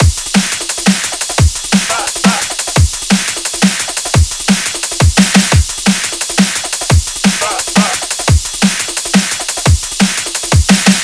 003 Drum And Bass Piano 174 Bpm
标签： 174 bpm Drum And Bass Loops Piano Loops 2.02 MB wav Key : Unknown
声道立体声